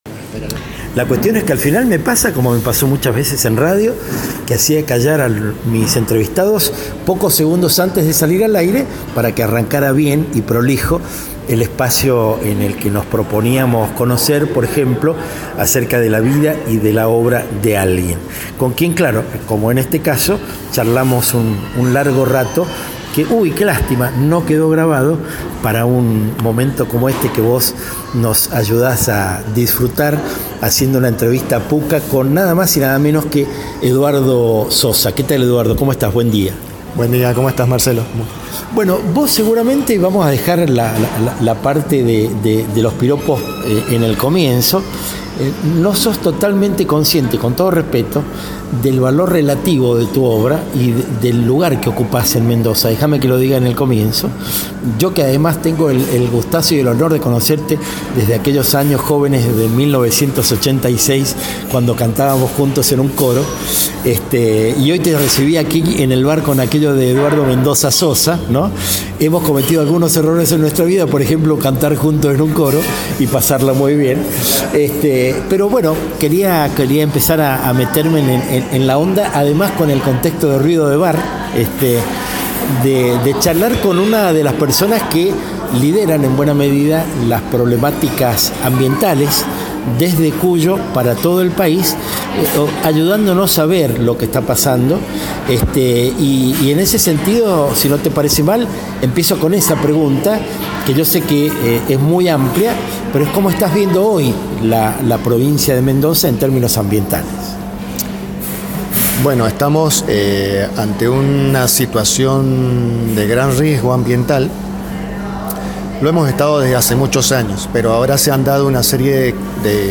Tomar un café caliente con medialunas en el bar Pukka, de San Martín 891 de Ciudad, una vez más fue refugio para tramar un cúmulo de ideas y sueños.
Bienvenido a otra charla imperdible en nuestro diario. https